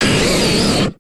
Pokemon X and Pokemon Y Cry Variations: